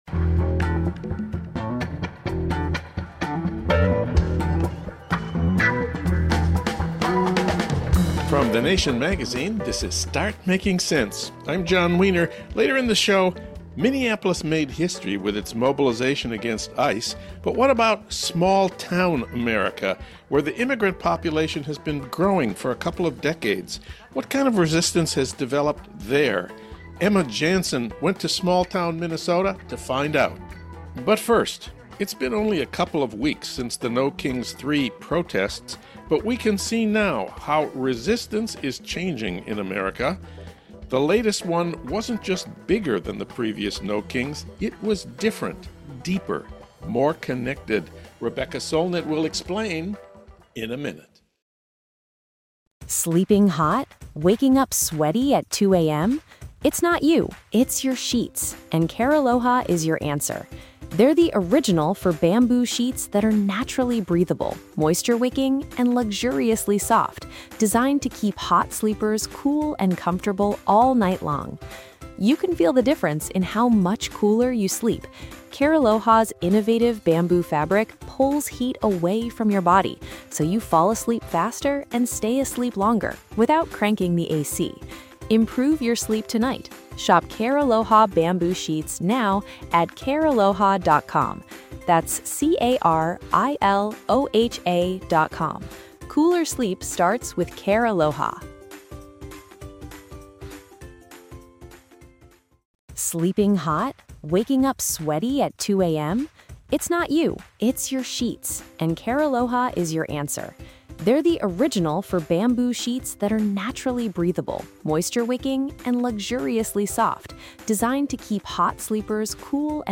Political talk without the boring parts, featuring the writers, activists and artists who shape the week in news.